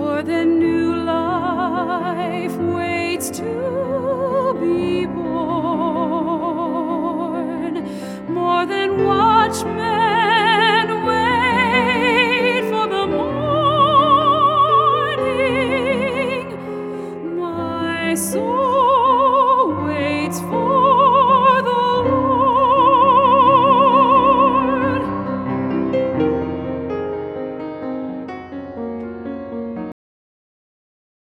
Inspirational With A Classical Twist.